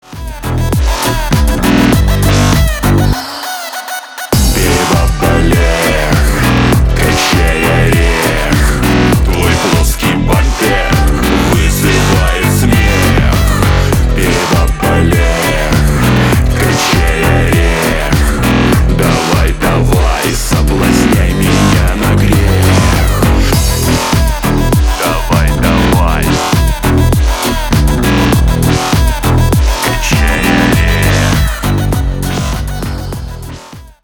веселые
смешные